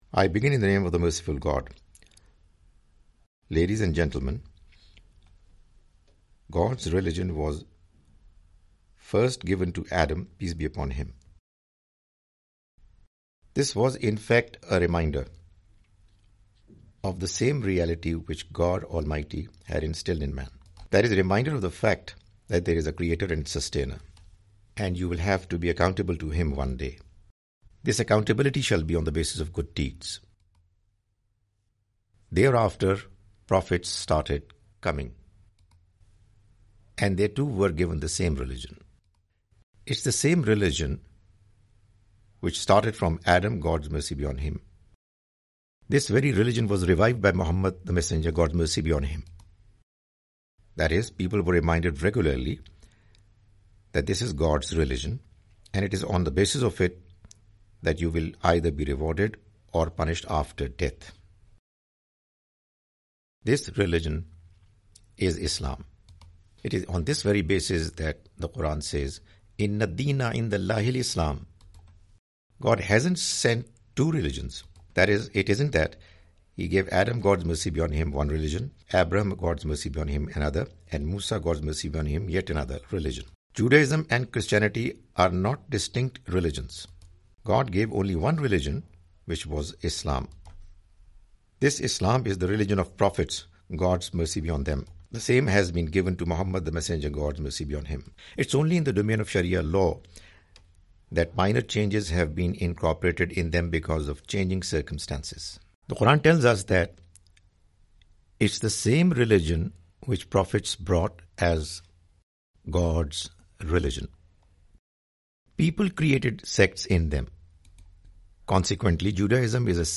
The Message of Qur'an (With English Voice Over) Part-9
The Message of the Quran is a lecture series comprising Urdu lectures of Mr Javed Ahmad Ghamidi.